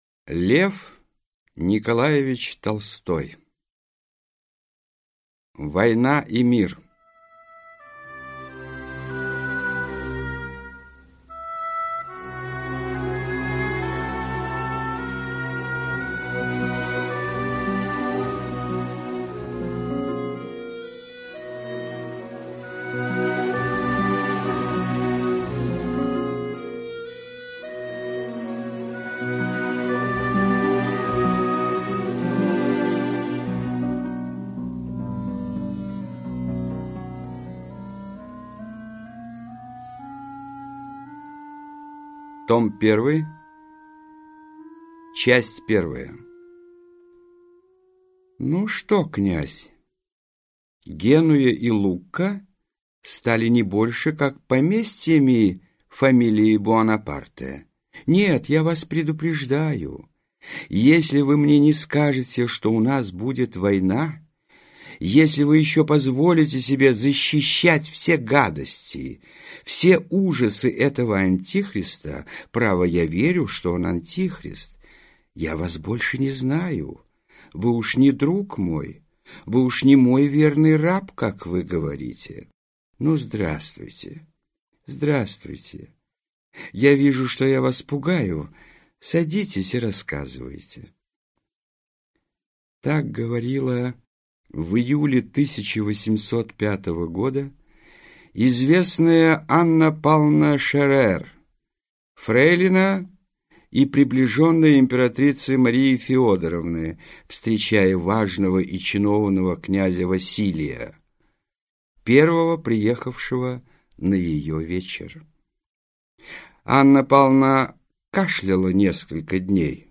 Аудиокнига Война и мир. Том 1 | Библиотека аудиокниг